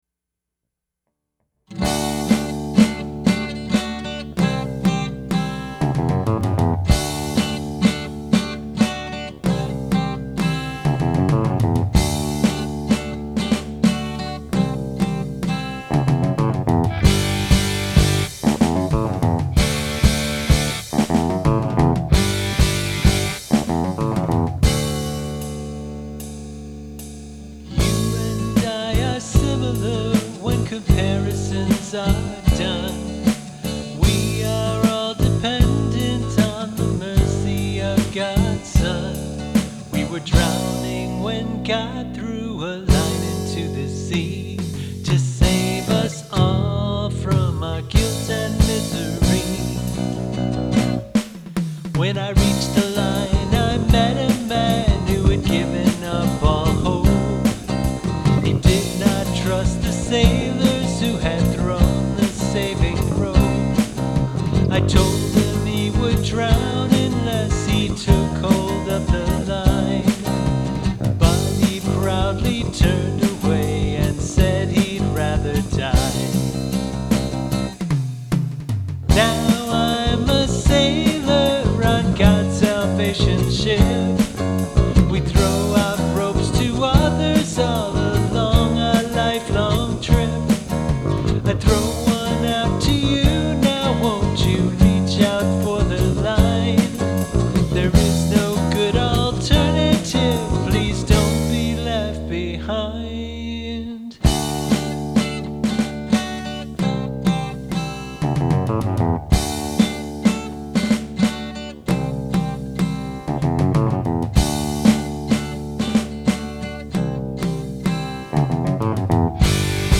Vocals, Guitars, Bass
Lead Guitar
Drums And Percussion